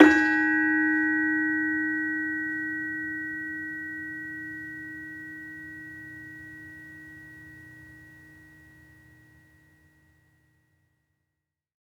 Gamelan Sound Bank
Saron-3-D#3-f.wav